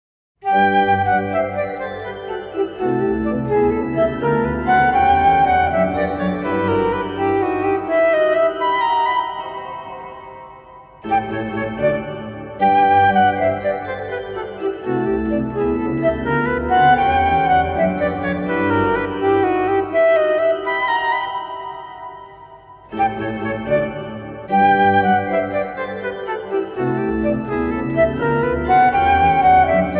Rieger-Orgel in der Basilika der Abtei Marienstatt
Lebhaft, doch nicht zu sehr